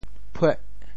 “坺（墢）”用潮州话怎么说？
坺（墢） 潮语发音 展开其他区域 潮州 puêh8 潮州 0 中文解释 翻起的土 用犁翻起的一块块土坷垃。